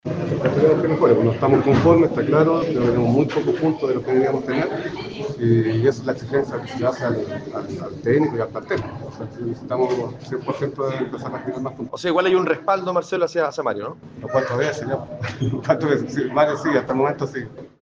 Una vez terminado el compromiso en el cual el elenco de La Araucanía solo rescato un punto jugando frente a Curicó Unido en el estadio Germán Becker el Presidente de la Institución Albiverde Marcelo Salas habló con los medios de comunicación para expresar su disconformidad con la campaña que se ha dado en esta temporada y que ya van 8 fechas del campeonato de ascenso de Primera B. Pese a ello el timonel albiverde manifestó que el DT continuará al mando de Deportes Temuco “hasta el momento”.